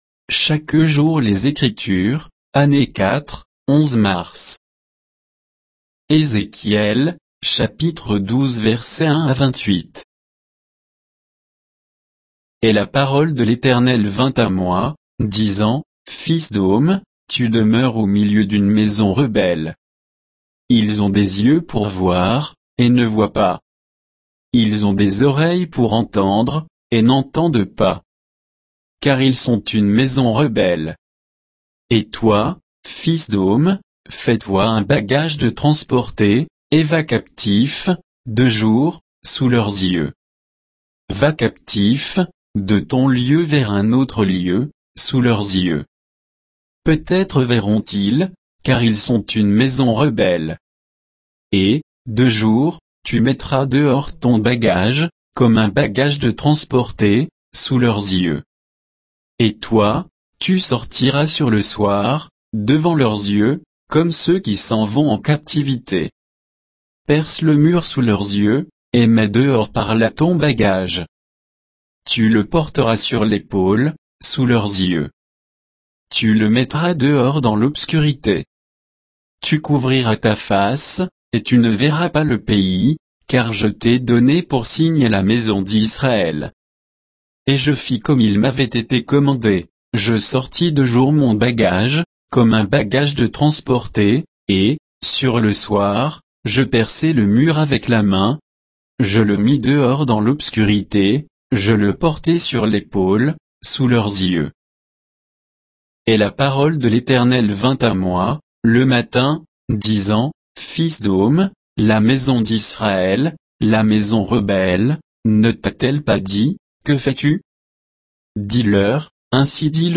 Méditation quoditienne de Chaque jour les Écritures sur Ézéchiel 12